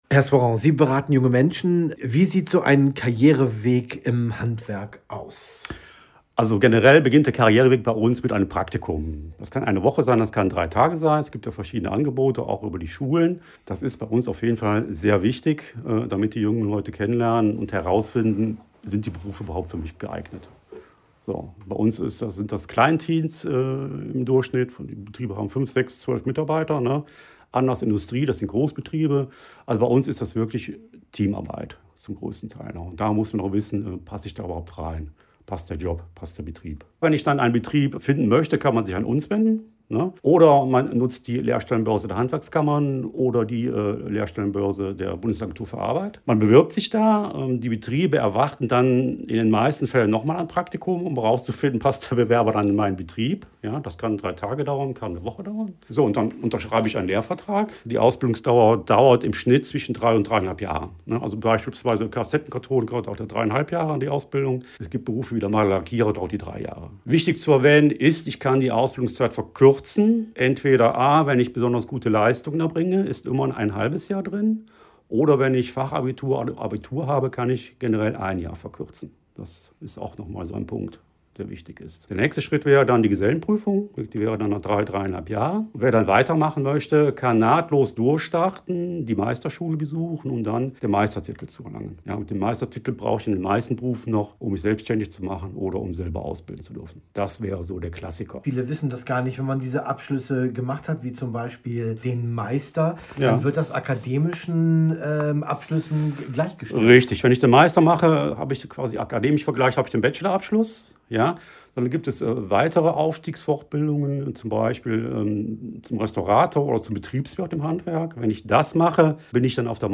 Radiobeiträge: Karriereleiter im Handwerk